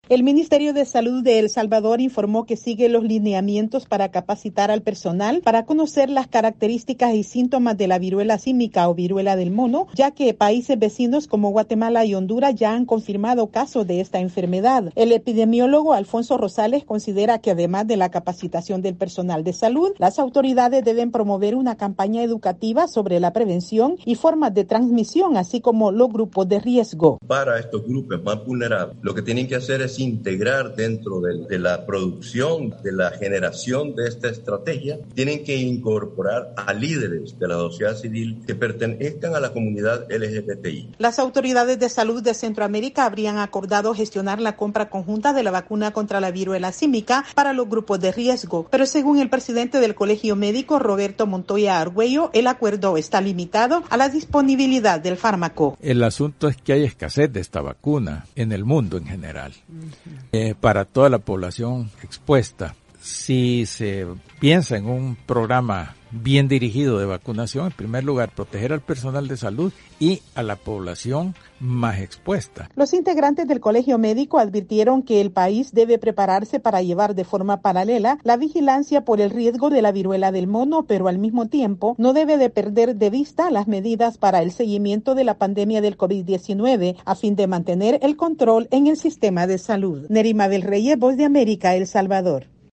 El Salvador debe preparar la infraestructura necesaria y una campaña de educación por el riesgo actual de propagación de la viruela símica, según advirtieron especialistas sanitarios. Desde San Salvador informa la corresponsal de la Voz de América